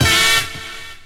68_02_stabhit-A.wav